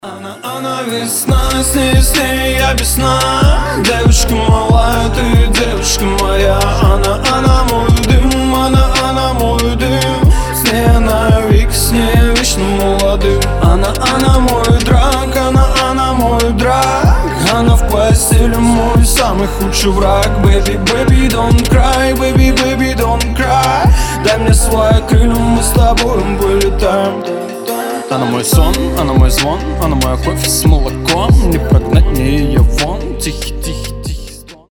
• Качество: 320, Stereo
мужской вокал
лирика
русский рэп
чувственные